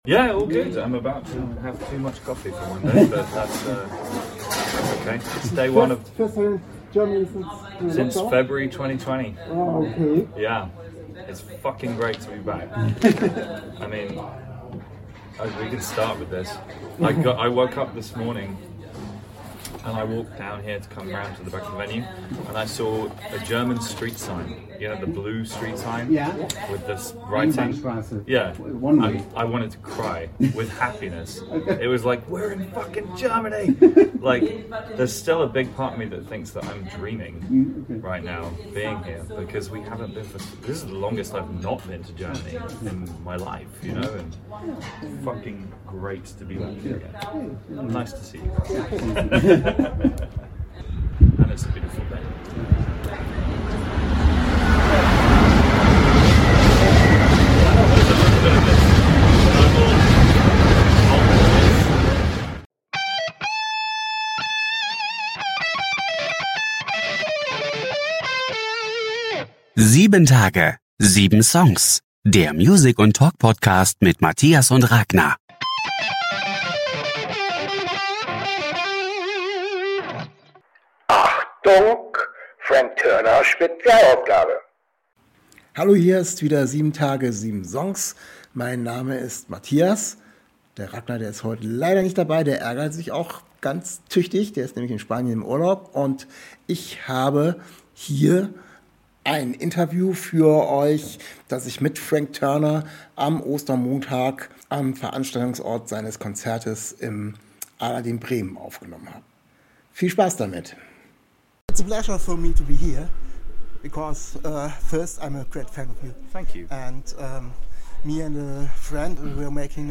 Interview with Frank Turner in Bremen, Germany, before he kicked off his concert. We talked about his current album, his excitement to be back in Germany, musical influences, his new studio and how he is supporting other young artists, bands and singer-songwriter.